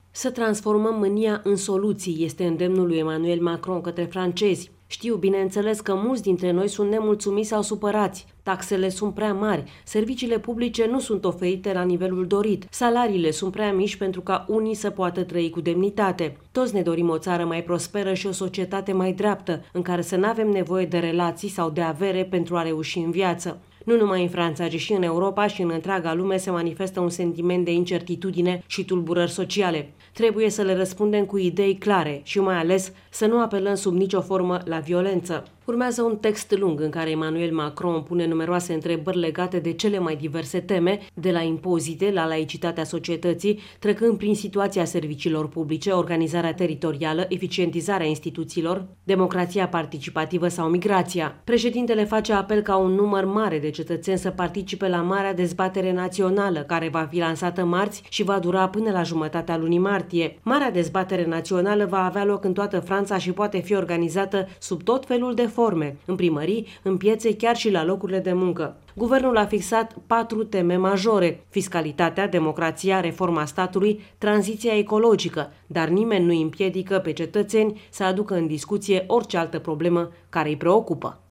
transmite din Paris: